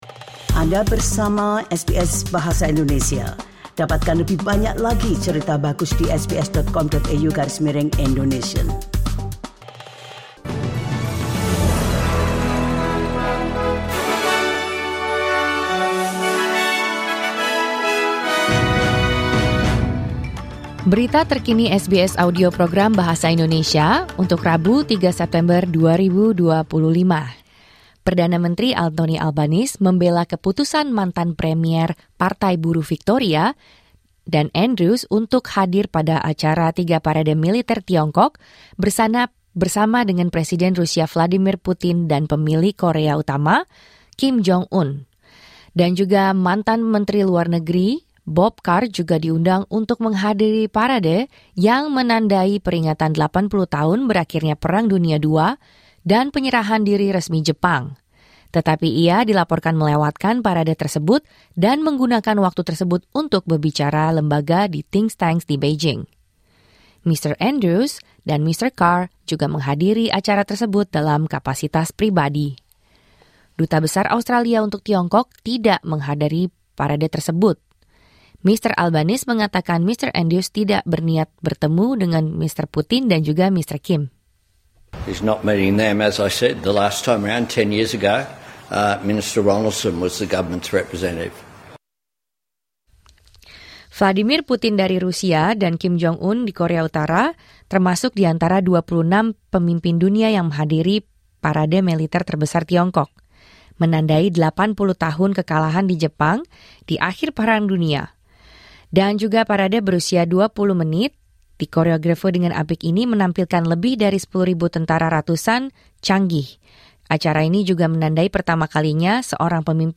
Berita Terkini SBS Audio Program Bahasa Indonesia - 3 September 2025